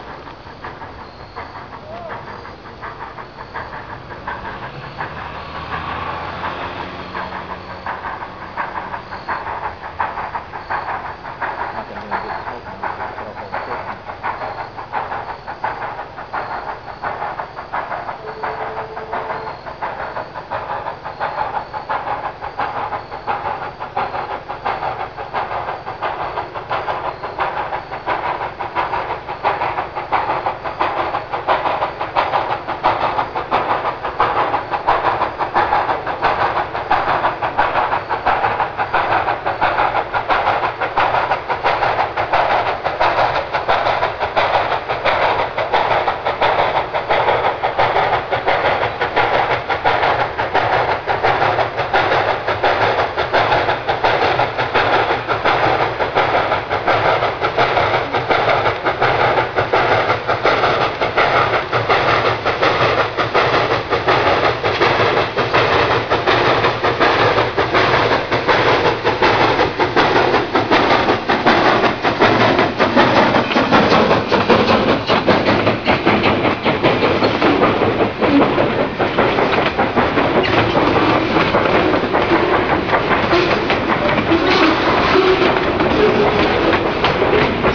BRW #60 - also during the October 2000 mixed freight - this time, the mixed has finished switching cars at the TV track, and is working upgrade along side Pennsylvania Avenue, Flemington